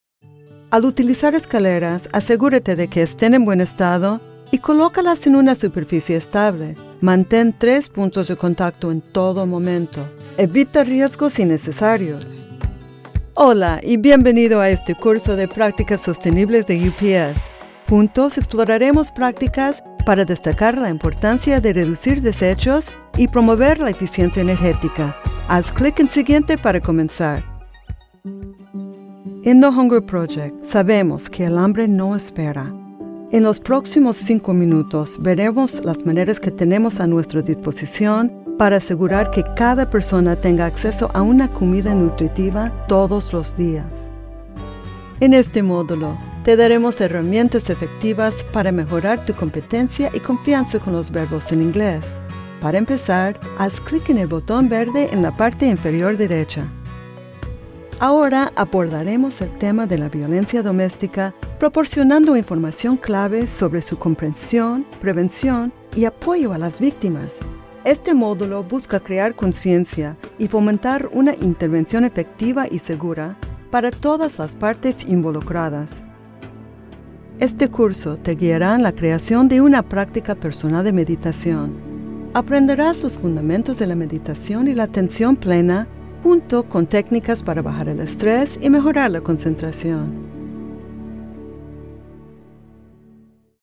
Spanish E learning Demo
Spanish - Neutral
North American English, Latin American Spanish
Young Adult
Middle Aged